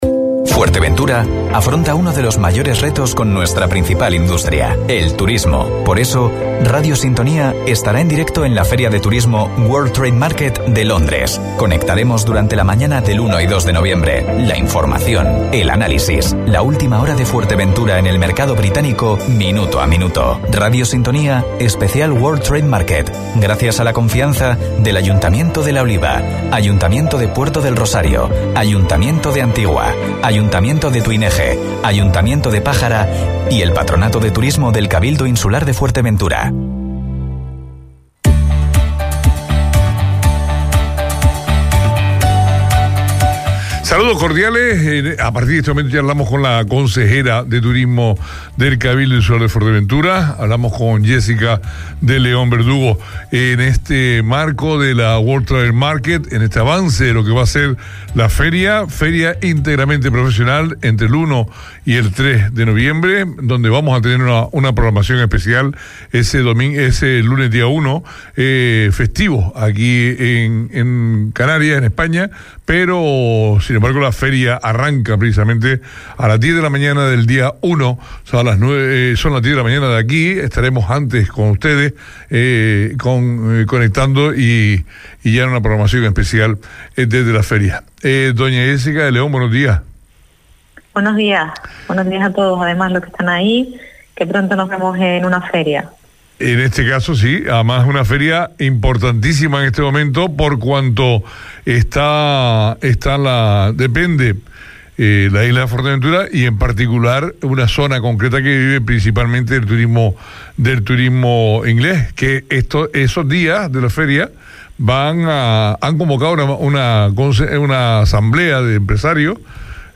Especial Turismo Feria de Londres, entrevista a Jessica de León Verdugo, consejera de Turismo del Cabildo – 29.10.21 Deja un comentario